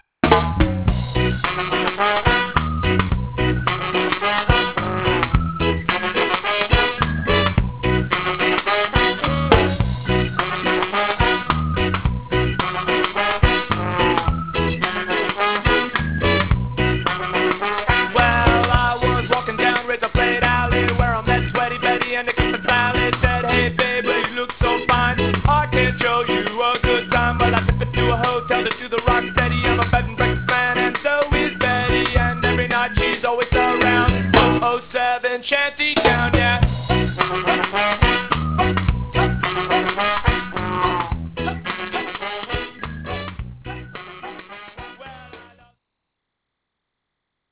LineupFive boys, two girls and one dead chicken.